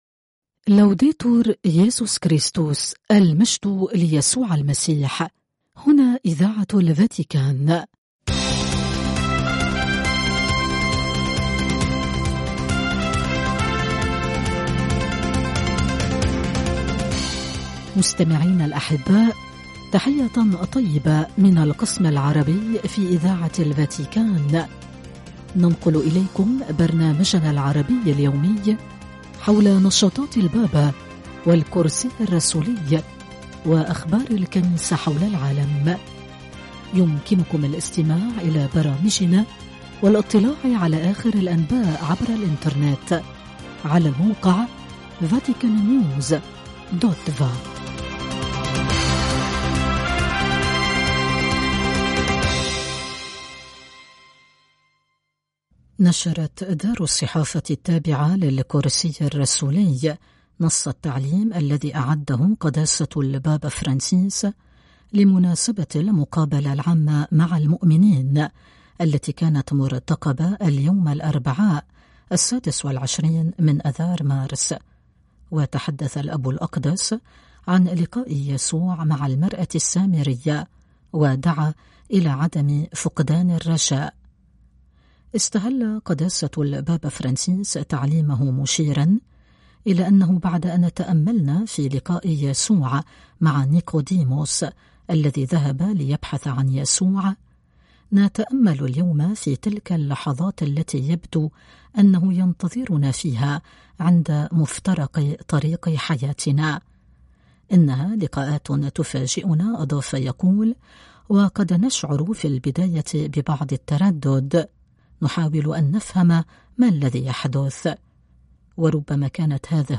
أخبار